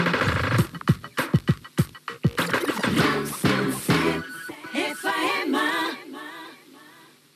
Indicatiu de l'emissora